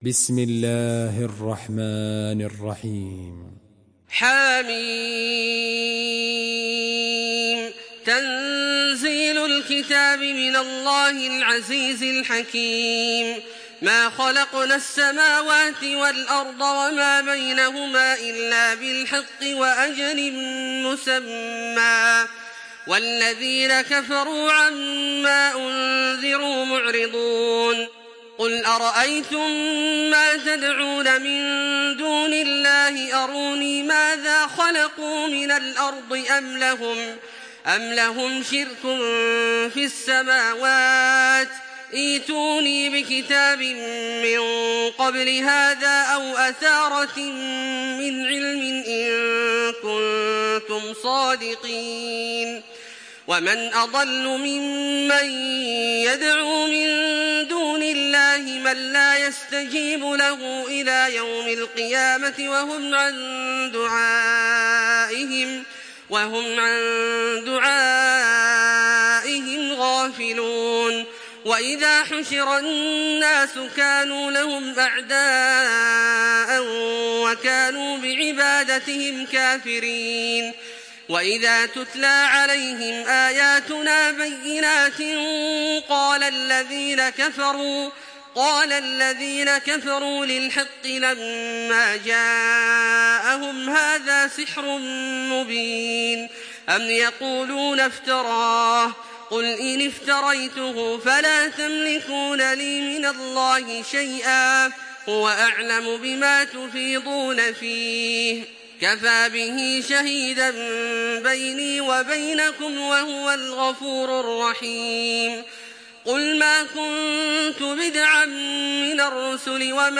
Surah Al-Ahqaf MP3 in the Voice of Makkah Taraweeh 1426 in Hafs Narration
Listen and download the full recitation in MP3 format via direct and fast links in multiple qualities to your mobile phone.